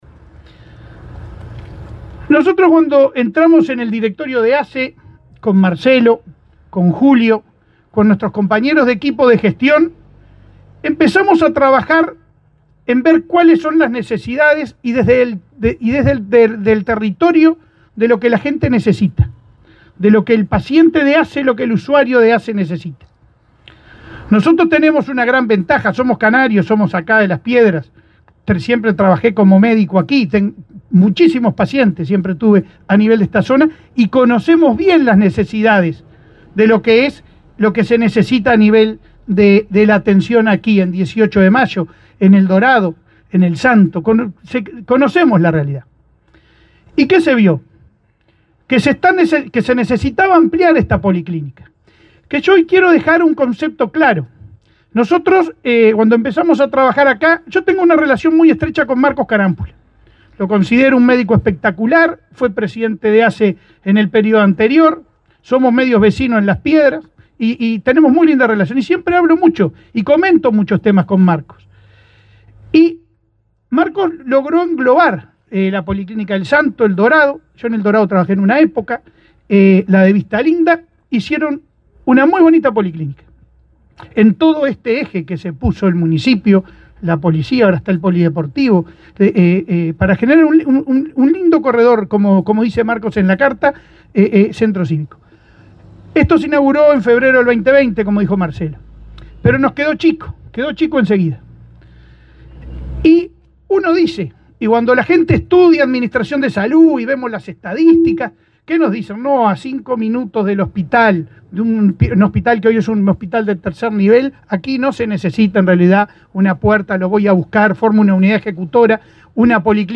Palabras del presidente de ASSE, Leonardo Cipriani
Palabras del presidente de ASSE, Leonardo Cipriani 03/05/2024 Compartir Facebook X Copiar enlace WhatsApp LinkedIn El presidente de ASSE, Leonardo Cipriani, participó de la inauguración de la puerta de emergencia y la base de SAME 105 de ASSE, este viernes 3, en el centro de salud 18 de Mayo de Canelones.